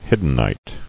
[hid·den·ite]